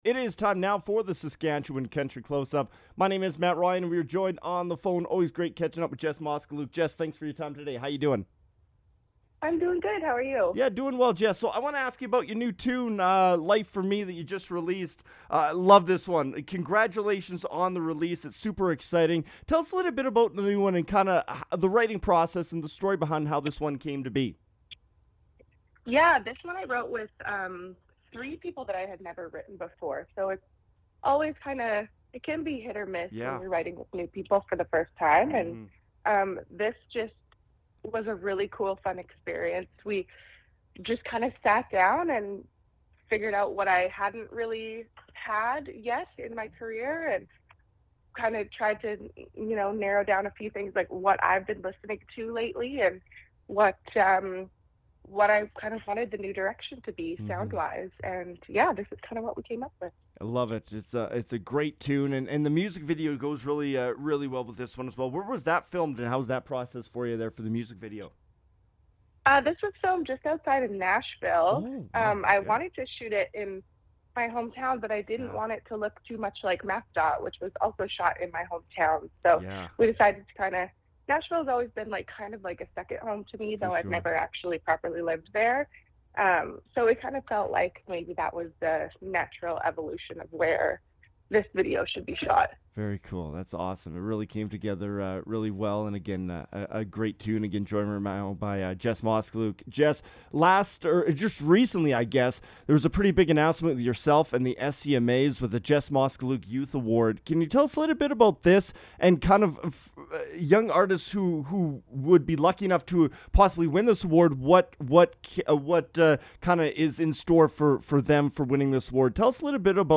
Versatile, Elevated, Authentic; We chat with Jess Moskaluke on the Closeup.